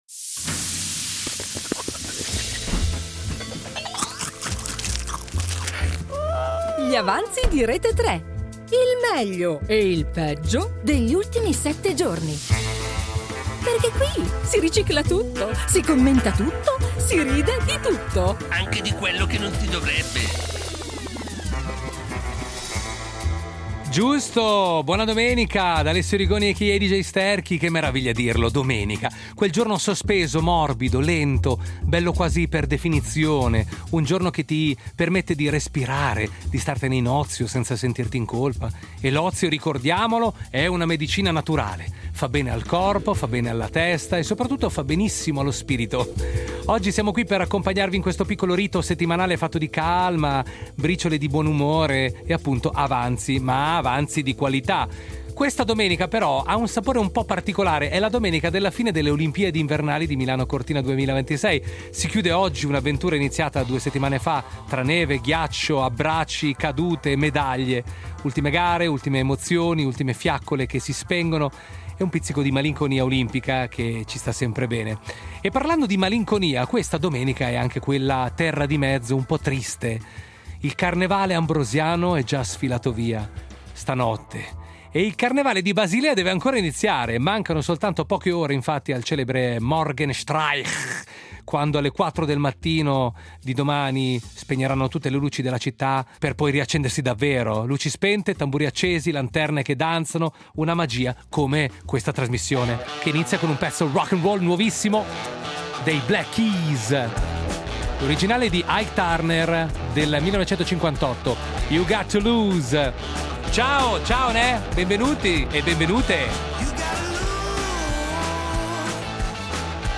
Gli Avanzi Gli avanzi 22.02.2026 58 min Contenuto audio Disponibile su Scarica Il pranzo della domenica è finito… ma in radio restano Gli avanzi.
Un programma che non butta via niente: si ricicla tutto, si commenta tutto, si ride di tutto.